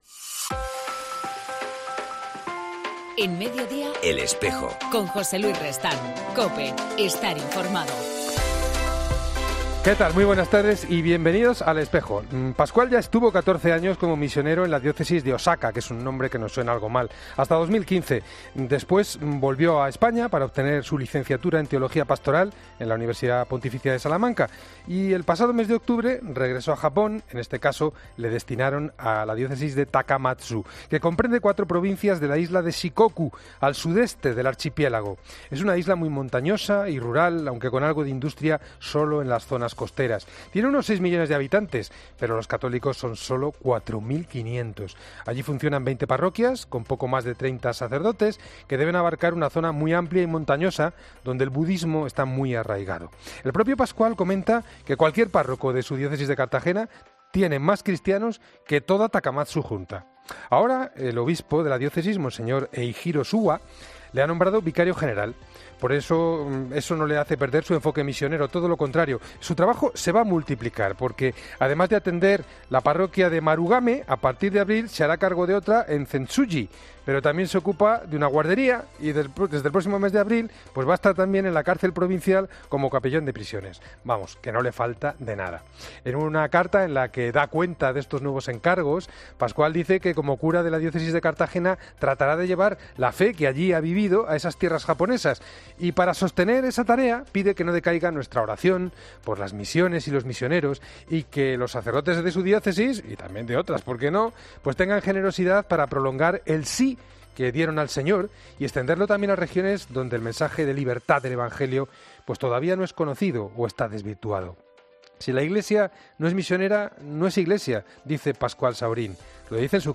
AUDIO: En 'El Espejo' del 15 de marzo hablamos con monseñor Enrique Benavent, obispo de Tortosa y presidente de la Comisión Episcopal para la...